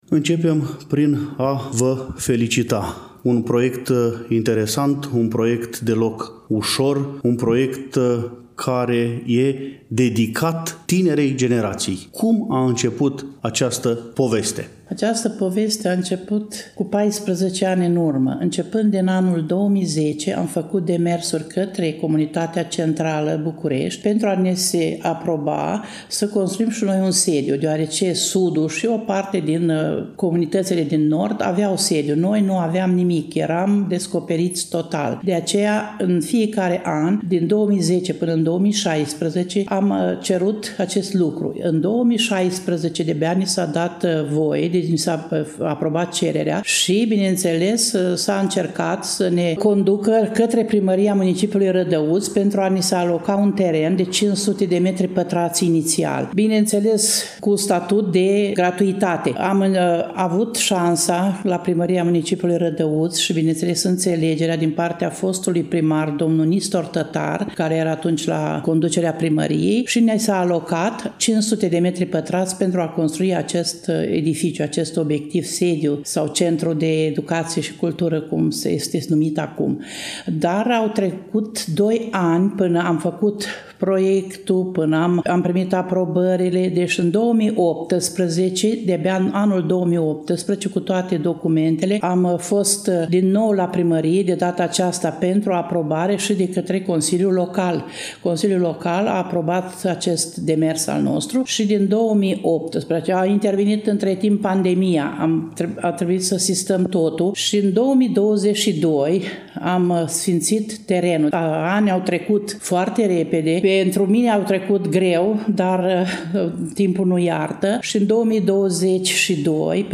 Nu ne rămânde decât să începem dialogul cu invitatul ediției de astăzi a emisiunii noastre și să aflăm povestea Centrului de Cultură și Educație din sânul Comunității Rușilor Lipoveni Rădăuți, cu accent pe descrierea acestuia.